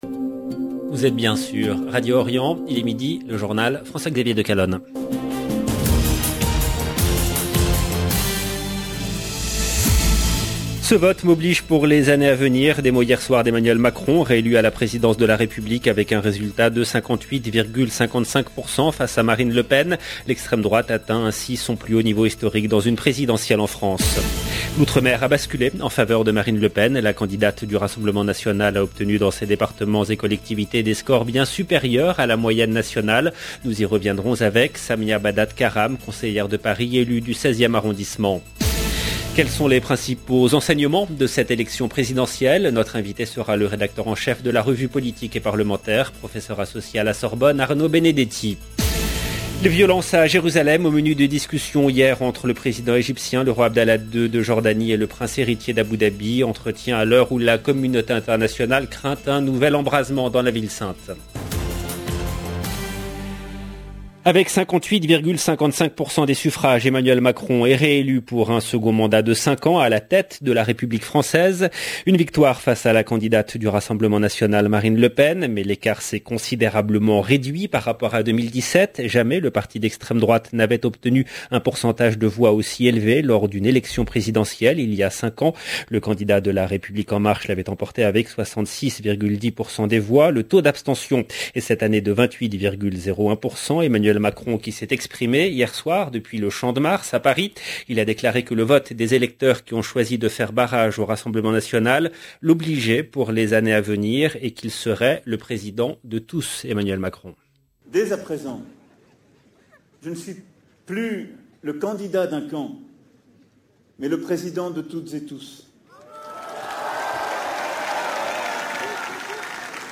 LE JOURNAL DE MIDI EN LANGUE FRANCAISE DU 25/04/22 LB JOURNAL EN LANGUE FRANÇAISE